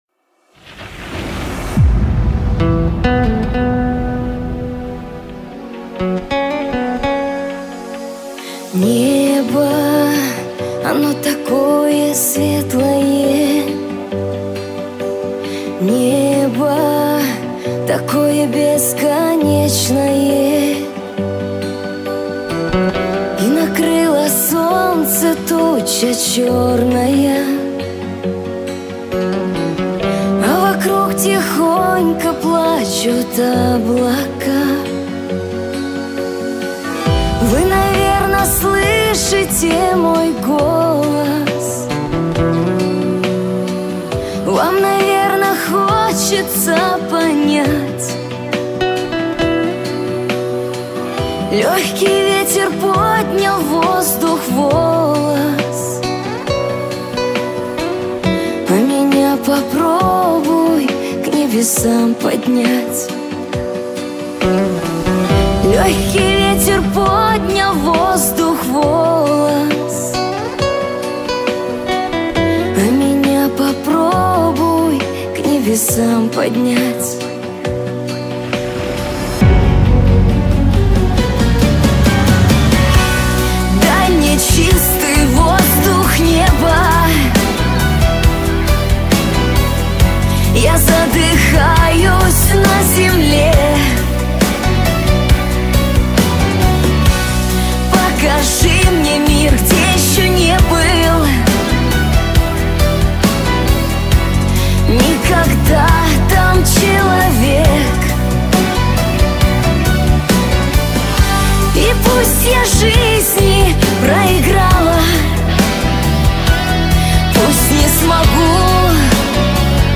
супер,шикарно! для любителей шансона